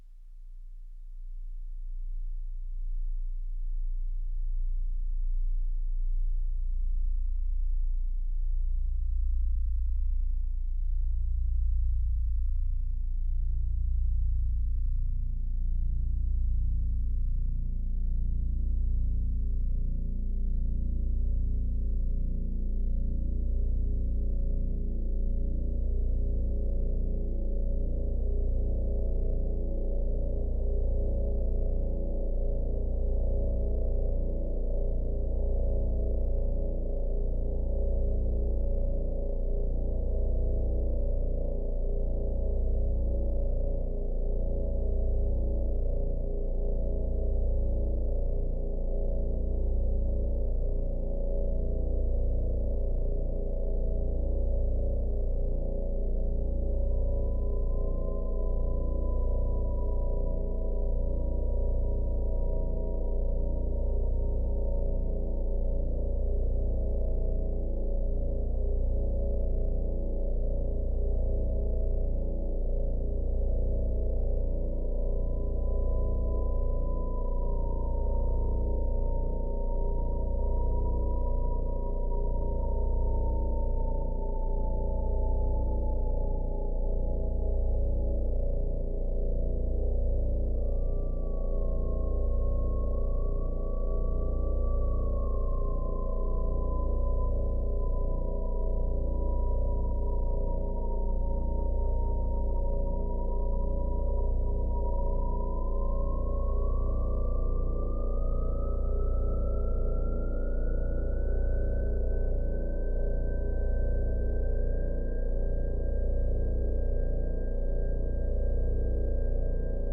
• Uniquement le A-100 version du moment.
• Type nuage complexe. Une voix de bourdon et une voix avec le 1630
• Les effets sont le n°15 de la table Behringer Xenyx X1622.
• Format : ogg (stéréo)